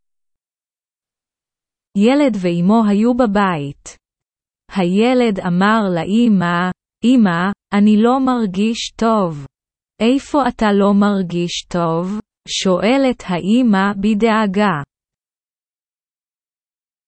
Texte Hébreu lu à haute voix à un rythme lent !